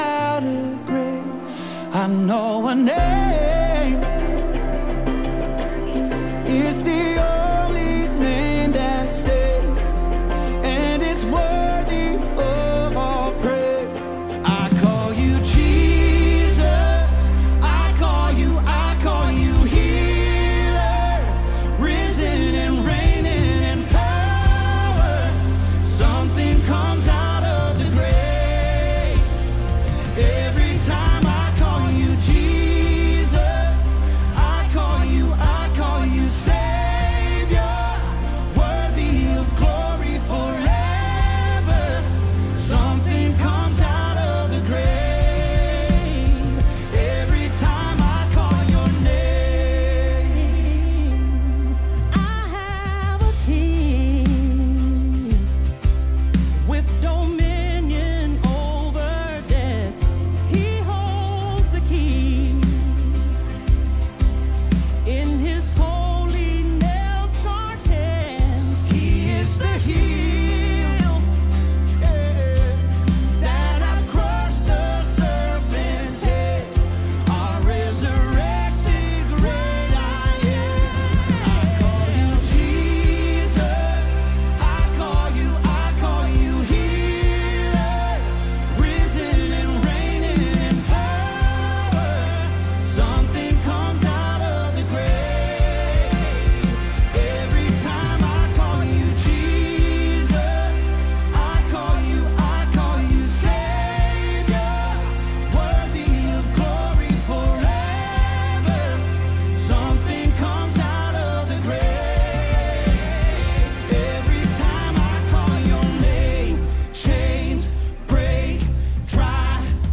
Christmas Sermon Series Emmanuel, God With Us